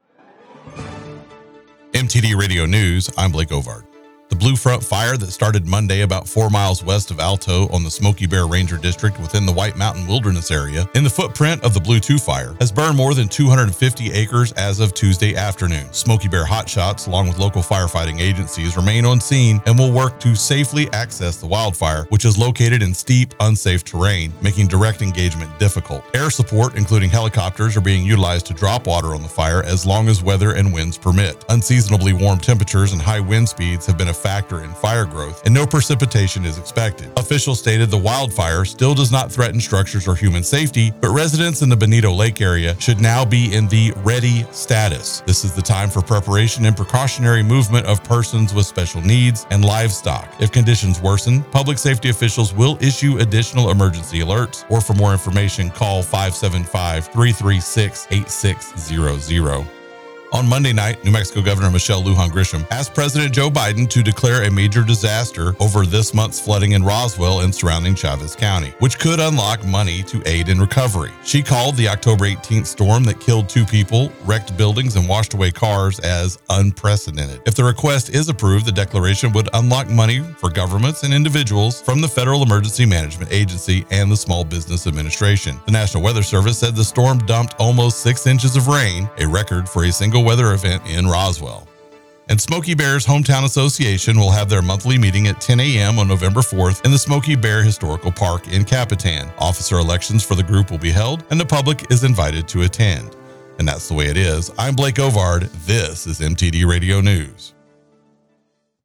Mix 96.7 NEWS RUIDOSO AND LINCOLN COUNTY